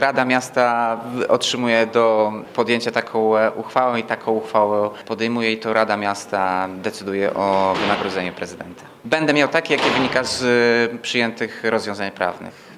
Kwestię wynagrodzenia jeszcze przed jego uchwaleniem skomentował Tomasz Andrukiewicz.